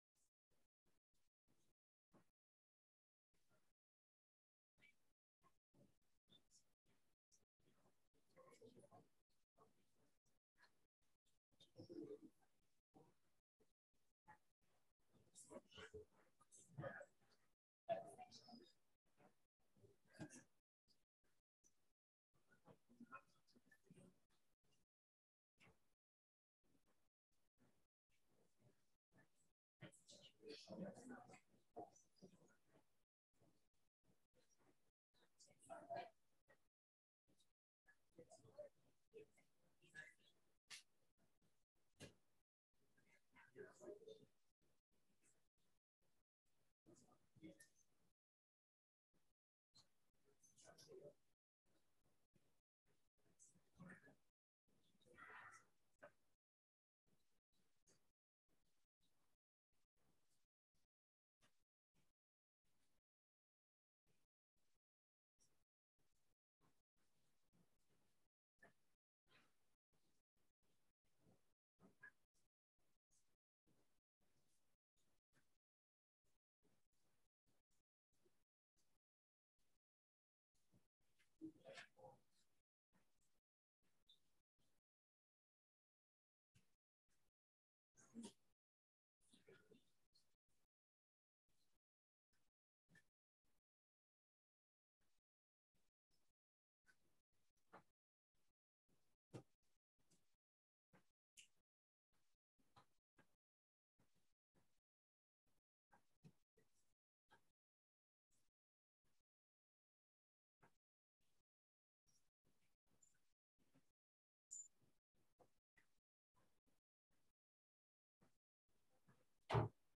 9/11/2022 Gospel Meeting Lesson 3